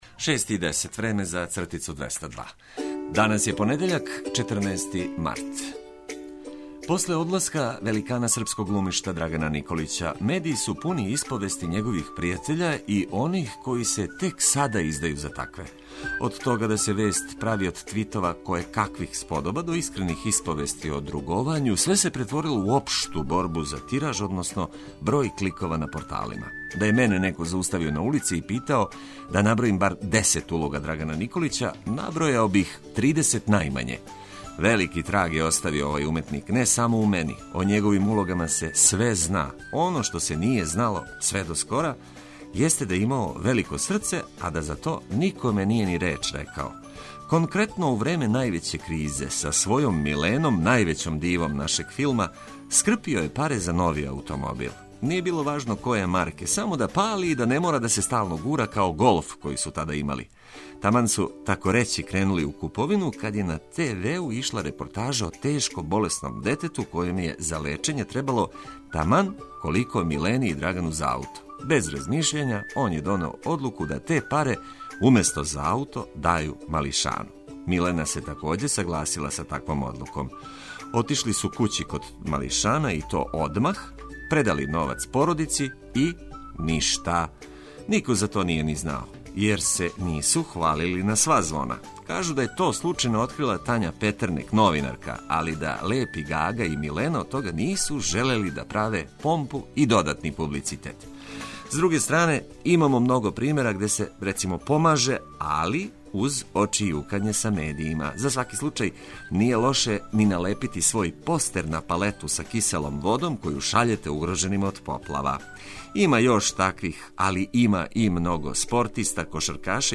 Почетак нове седмице обележиће извештаји са свих страна Србије о ситуацији након поплава, али и остале корисне информације и музика за буђење и лакши почетак дана.